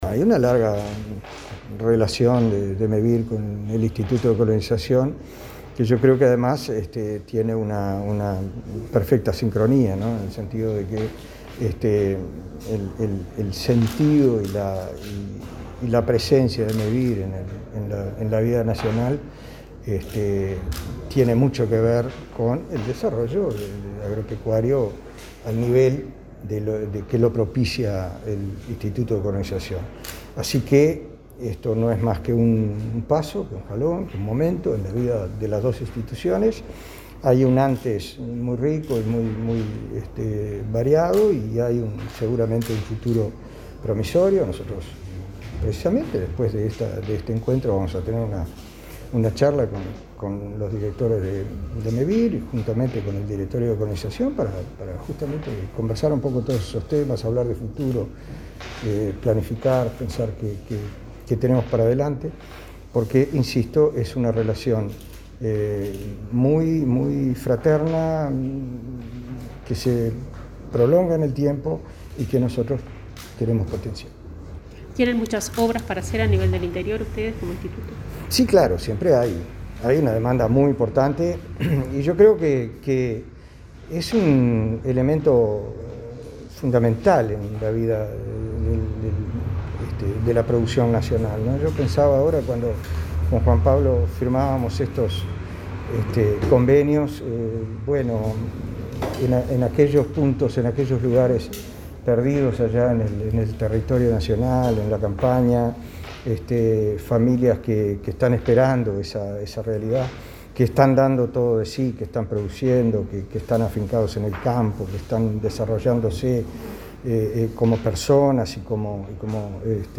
El titular de Colonización, Julio Silveira, dijo que se busca apoyar el desarrollo de pequeños productores rurales y sus emprendimientos familiares.